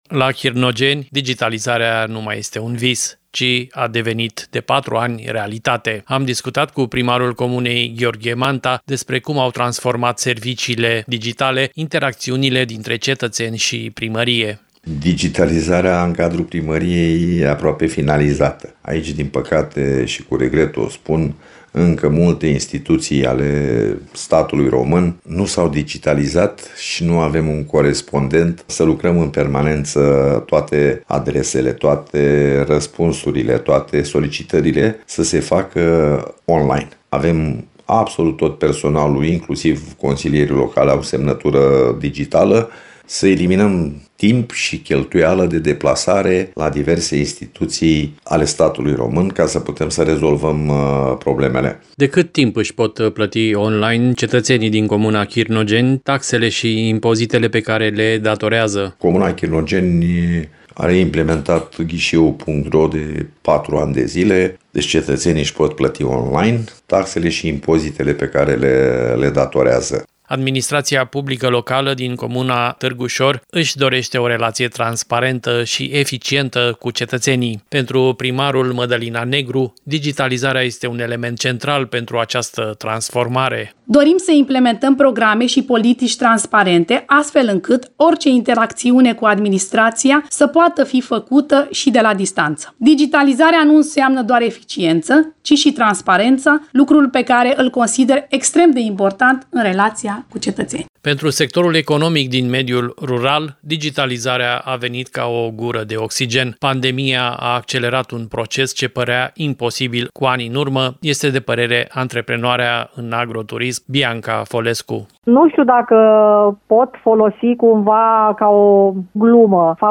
Gheorghe Manta, primar Chirnogeni
Mădălina Negru, primar Târgușor